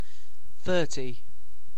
Ääntäminen
Vaihtoehtoiset kirjoitusmuodot threety Ääntäminen RP : IPA : /ˈθɜː.ti/ UK : IPA : [ˈθɜː.ti] GenAm: IPA : /ˈθɝ.ti/ US : IPA : [ˈθɝ.ti] Haettu sana löytyi näillä lähdekielillä: englanti Käännös Adjektiivit 1. trīcēnārius Määritelmät Substantiivit (slang) A rack of thirty beers .